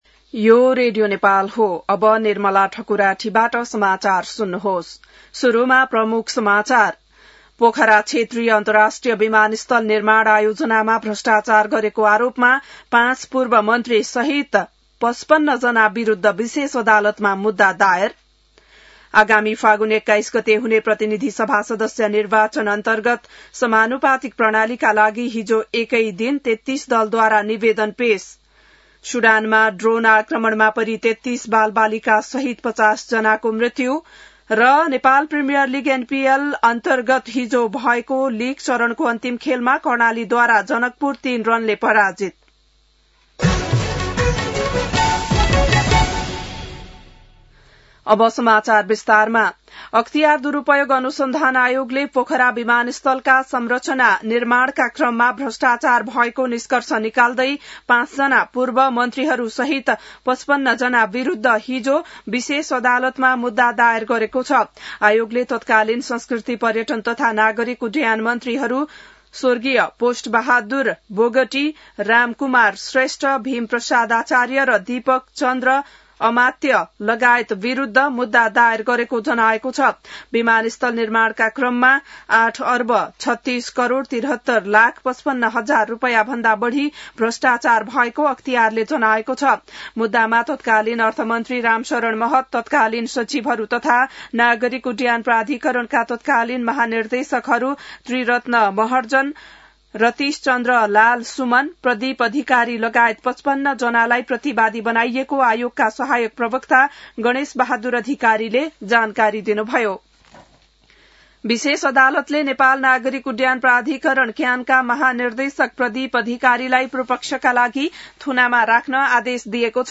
An online outlet of Nepal's national radio broadcaster
बिहान ९ बजेको नेपाली समाचार : २२ मंसिर , २०८२